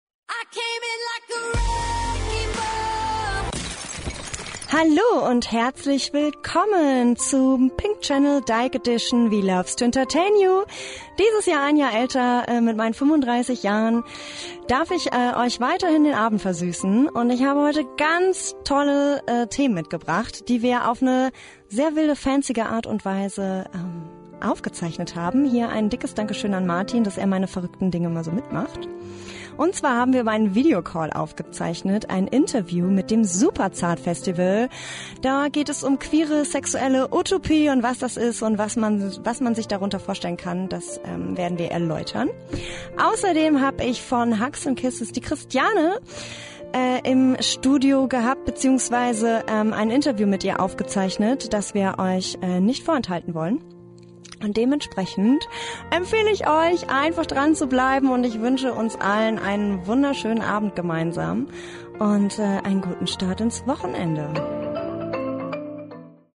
Radio-Talk im Queerformat